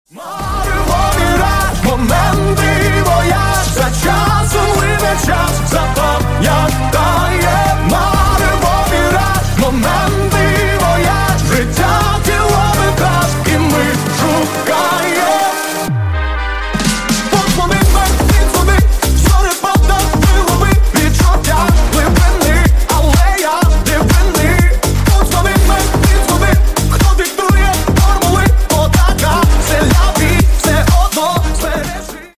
Рингтоны Ремиксы » # Танцевальные Рингтоны